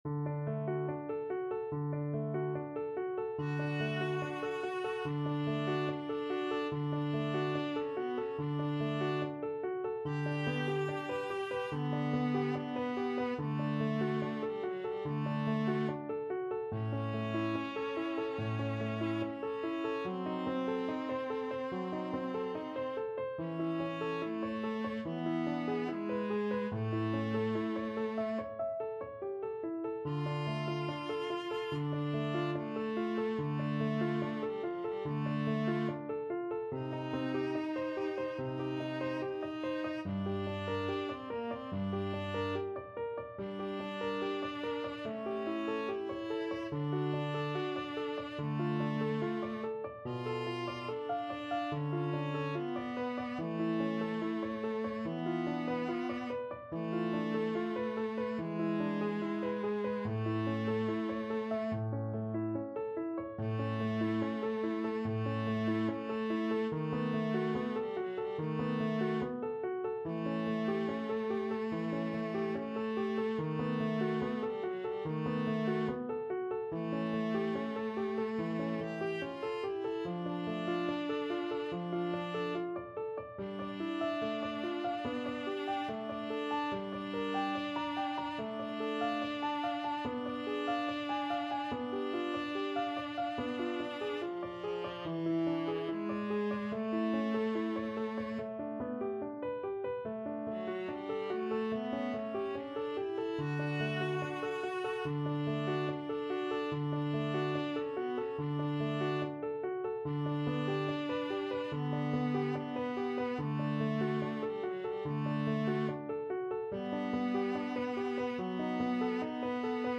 Andante =72